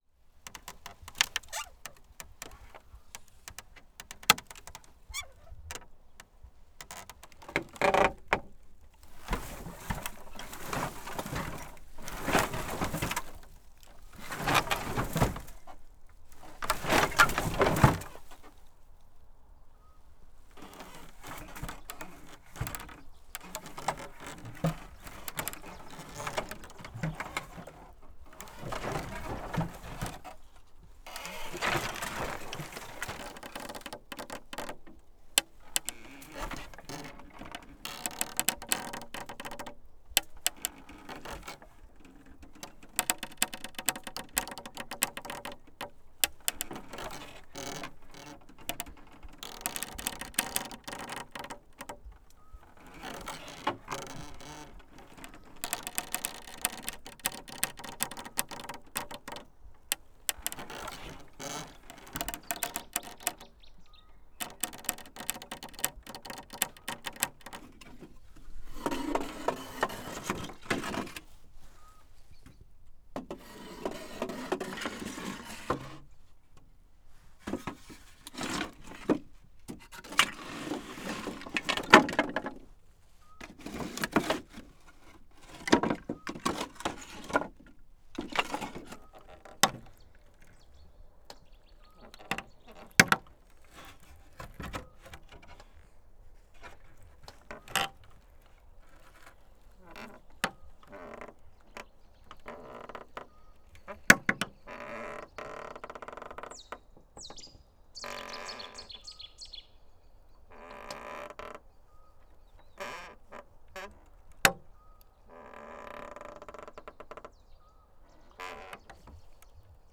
[ENG] Fruit pallets in the field.
palc3a9s-fruita.wav